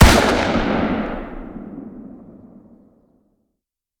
fire-dist-357mag-pistol-ext-01.ogg